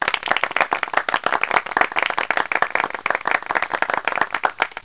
CLAP.mp3